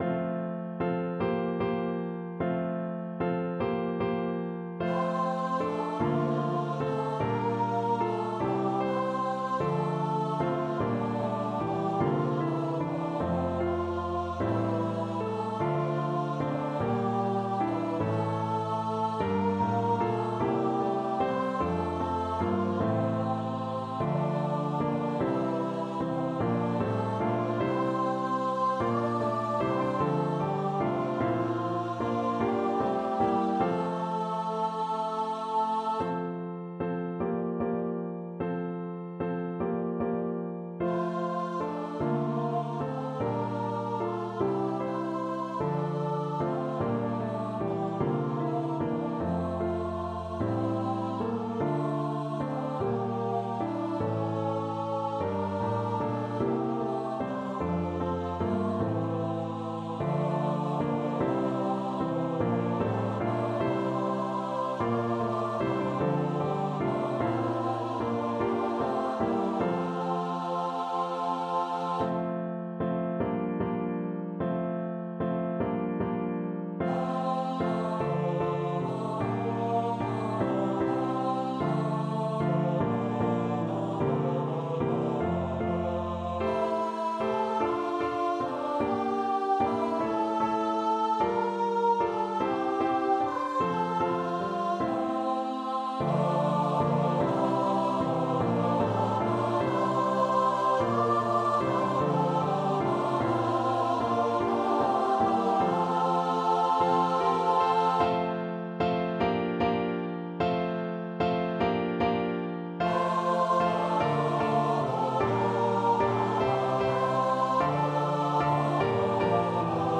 with music for SATB choir and piano accompaniment